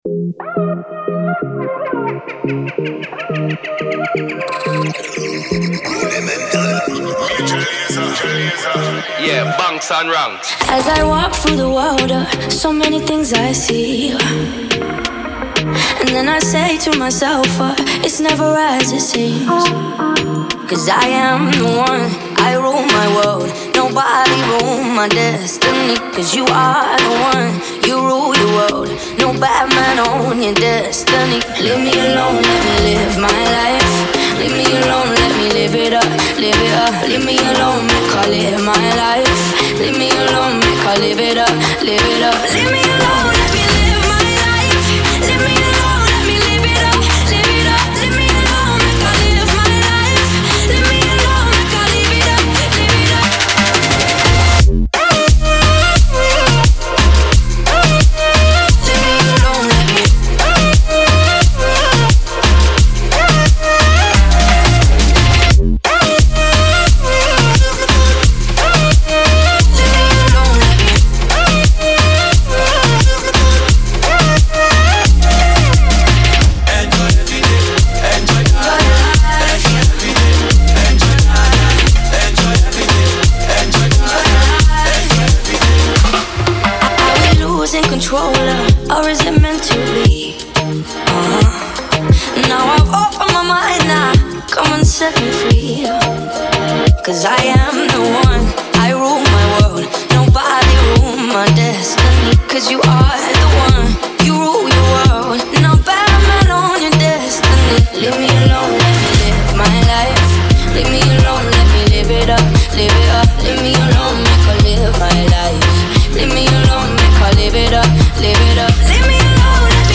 это зажигательная комбинация жанров EDM и регги